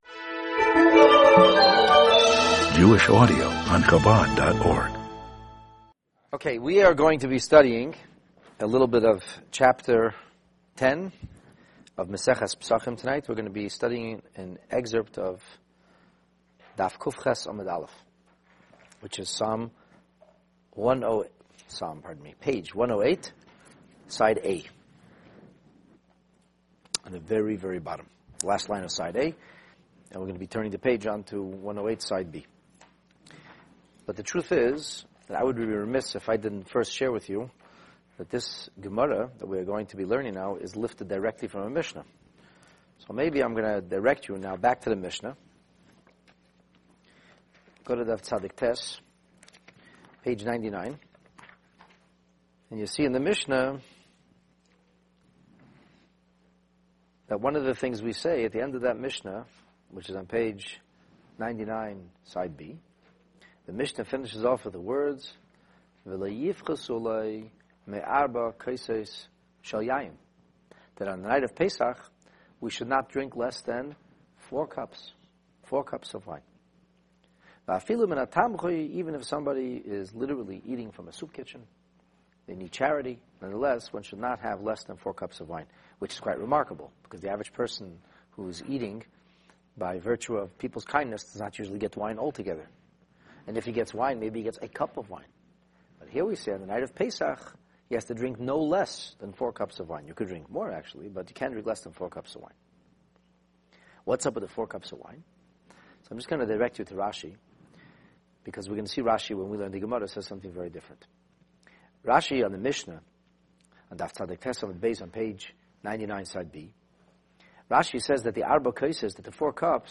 The Talmud on the Pesach Seder, Lesson 3 (Daf/Page 108a-b) This Talmud class traces the roots of the four cups through a tangled vine of sacred Torah traditions. Discover why this grape-based alcoholic drink is such an important component of the Passover Seder for both men and woman alike, as we shed new light on the leadership role the women played in catalyzing the Exodus.